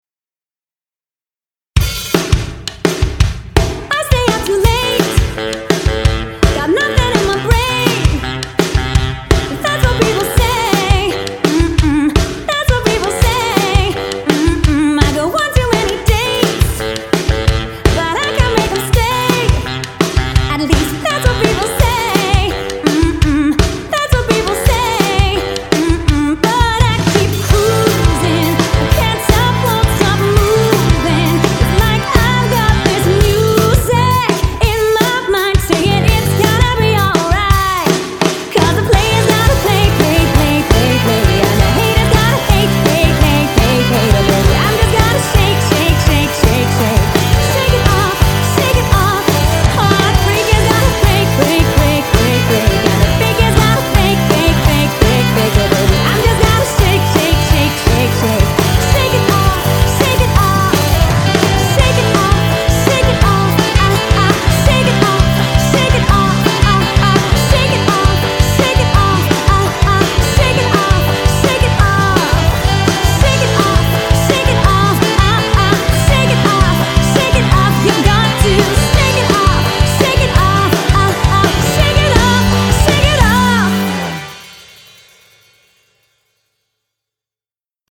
A sensational, high-calibre party band.
High Energy Corporate Band